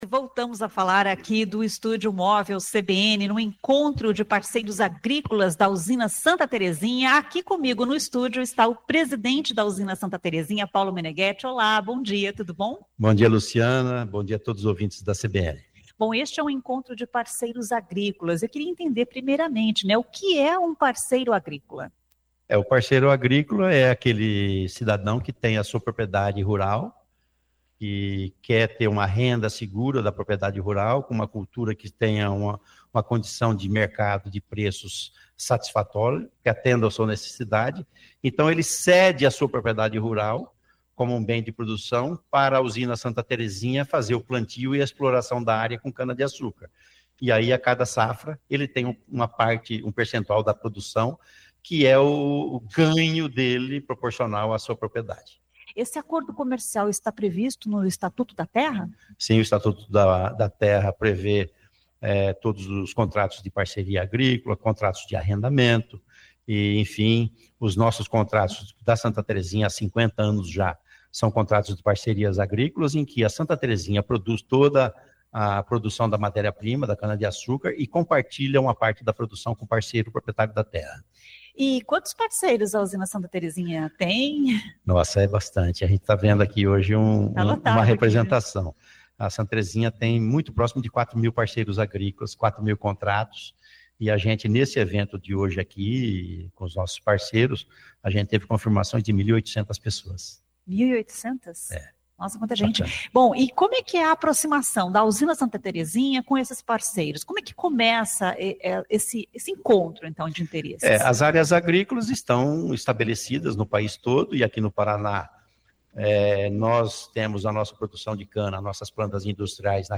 A entrevista foi realizada no Estúdio Móvel CBN, instalado na Paraná Expo, onde ocorre o Encontro de Líderes promovido pela Usina Santa Terezinha.